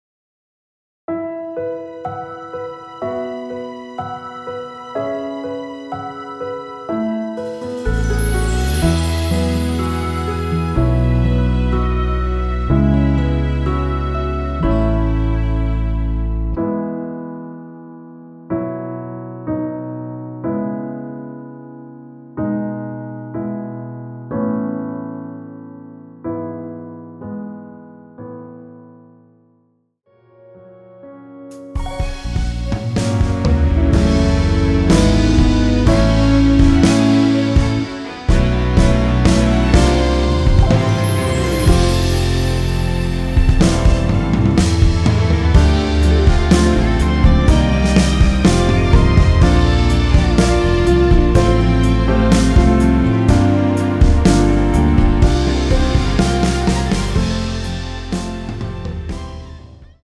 원키에서(-1)내린 MR입니다.
앞부분30초, 뒷부분30초씩 편집해서 올려 드리고 있습니다.